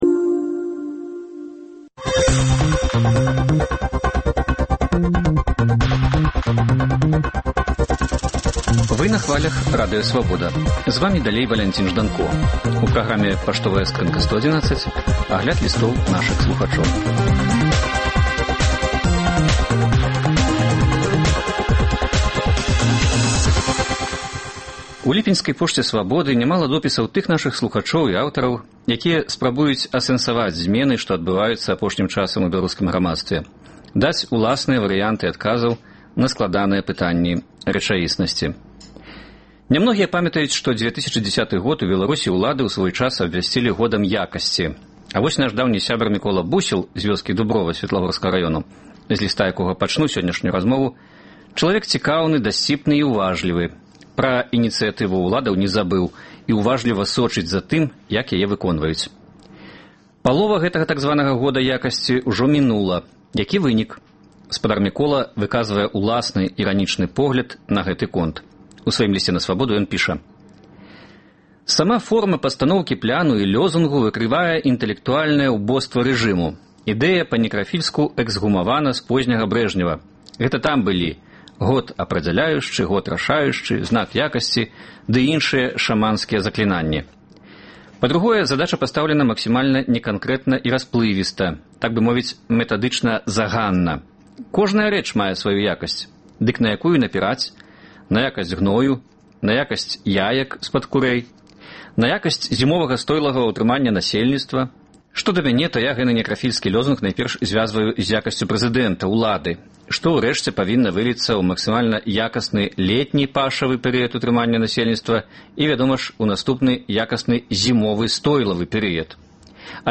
Лісты слухачоў на “Свабоду” чытае і камэнтуе